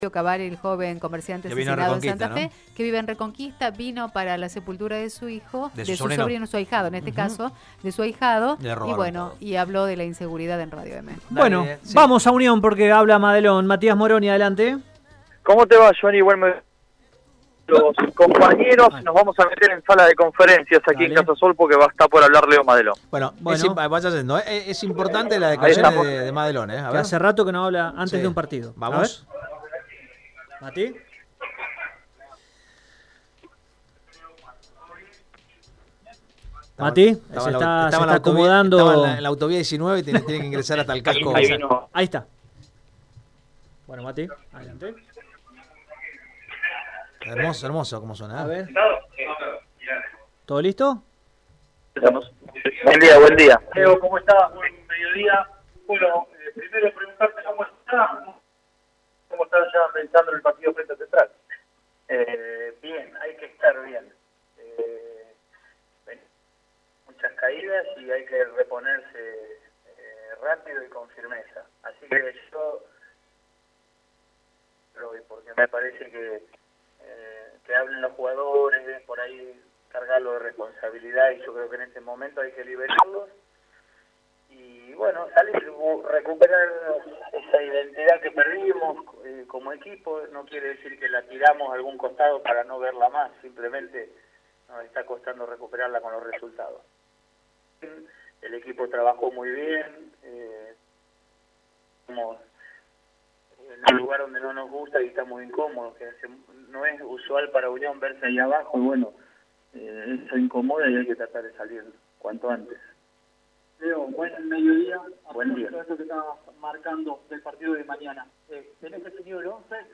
Luego del entrenamiento matutino de Unión, el DT Leonardo Carol Madelón, habló en conferencia de prensa previamente a enfrentarse este viernes con Rosario Central, por la Fecha Nº7 de la Superliga Argentina.